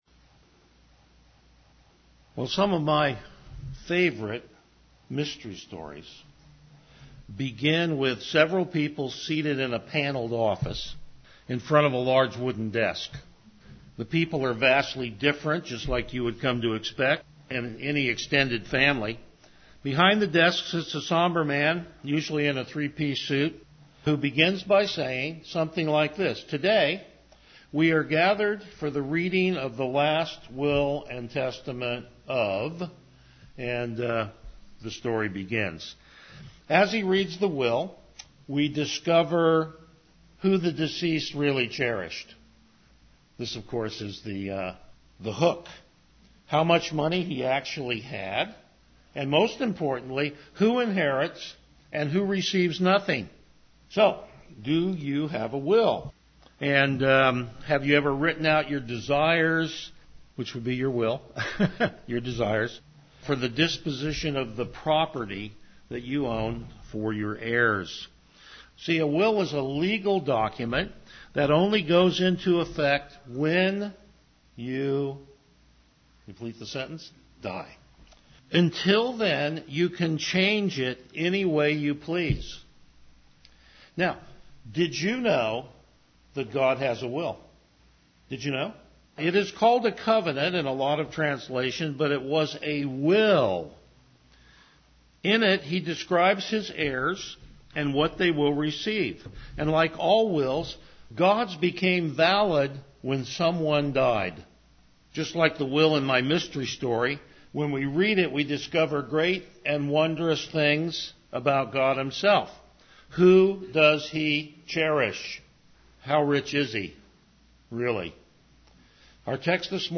Passage: Hebrews 9:15-28 Service Type: Morning Worship